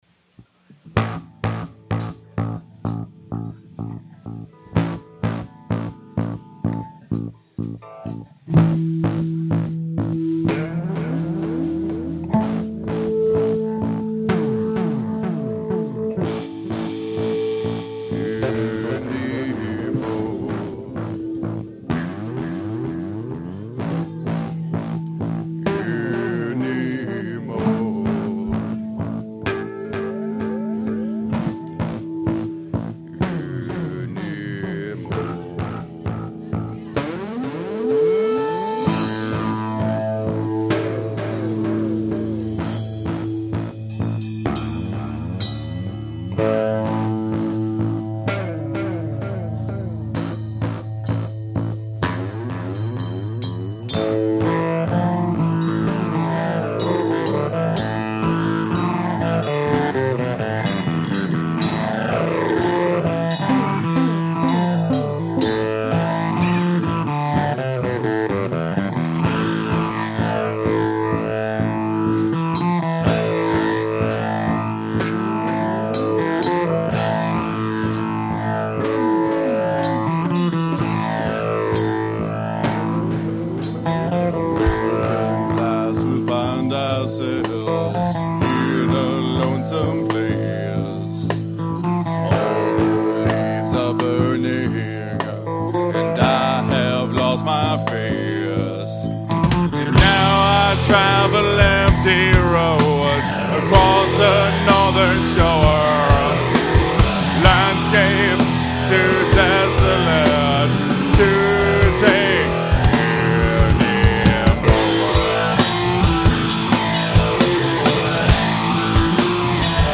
Live at The Red Sea